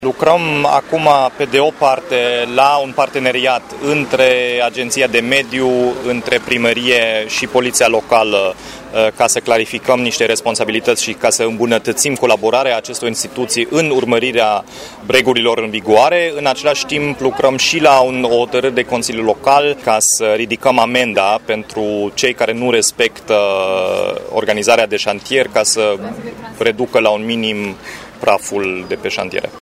Primarul Dominic Fritz a declarat că se lucrează la un proiect de hotărâre prin care constructorii să fie constrânși să reducă poluarea cu praf.
La vizita efectuată în teren, alături de ministrul Fondurilor Europene, pe șantierul de pe Calea Bogdăneștilor, primarul Dominic Fritz și ceilalți oficiali au putut simți pe propria piele ce înseamnă poluarea cu praf provenit de pe șantiere, mașinile care au circulat prin zona în care oficialii ofereau interviuri lăsând în urma lor un nor de praf.